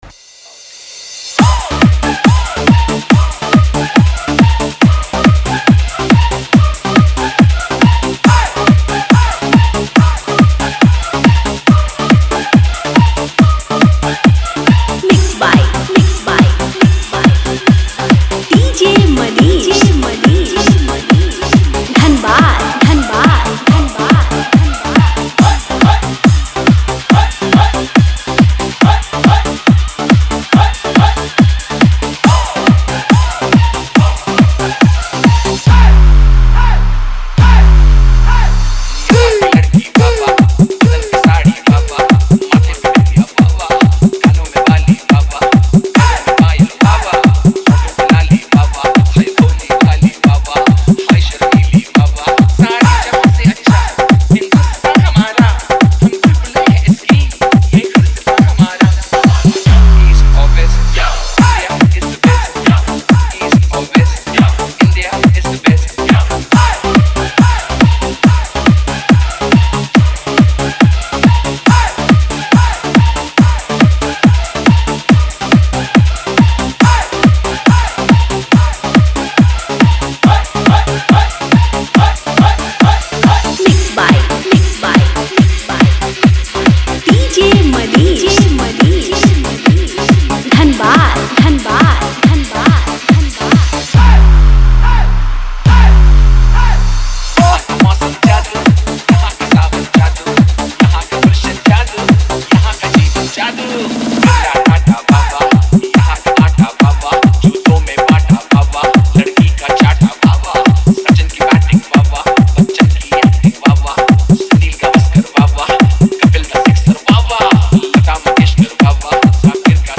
Category:  Desh Bhakti Dj Song 2019